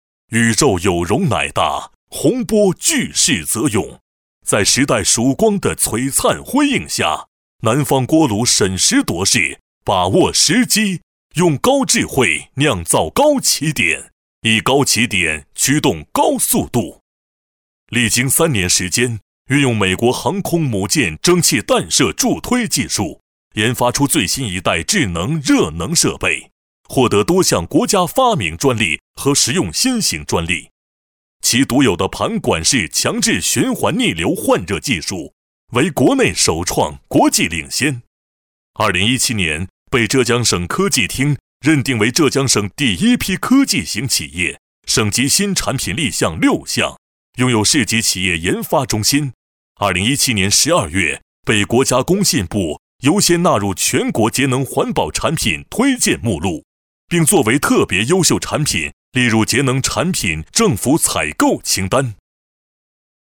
招标工程解说男20号
高性价比大气洪亮男中音，有力度。